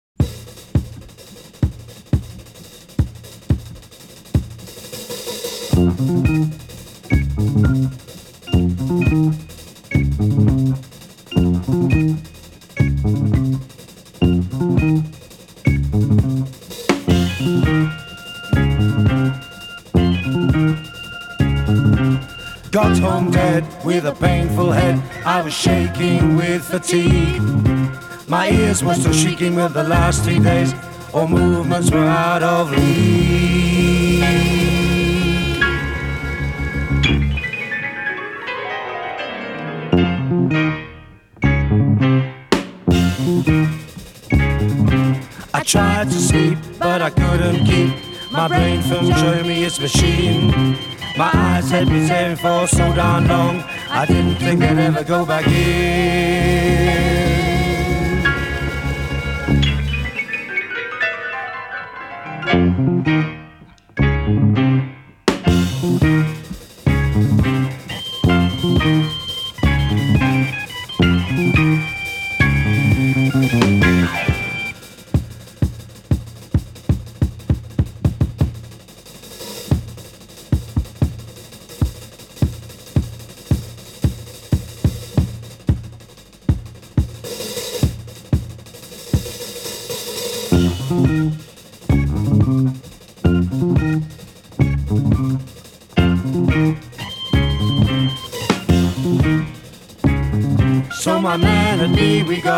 A fusion of jazz, rock and Afro influences